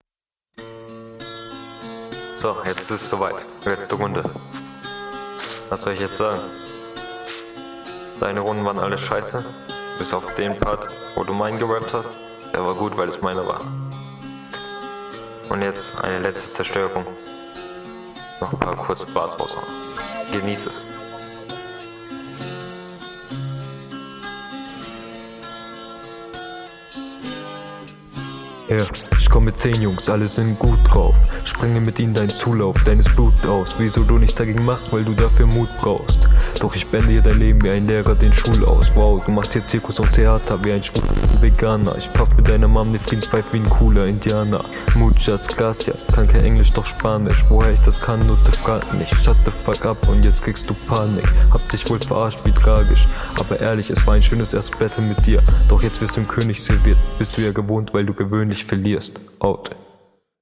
Flow: Du rappst wieder auf den Takt bist aber teilweise unverständlich!